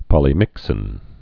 (pŏlē-mĭksĭn)